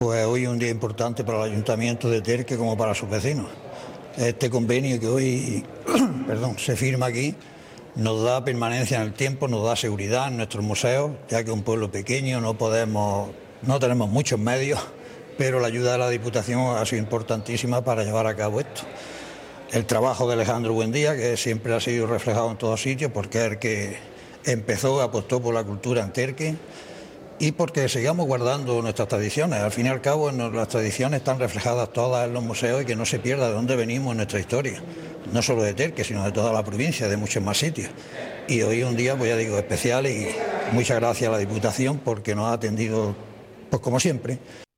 Esta mañana en el Palacio Provincial ha tenido lugar este encuentro con el objetivo de poner en marcha esta Fundación.
02-04_dipu_museos_de_terque__alcalde_de_terque_1.mp3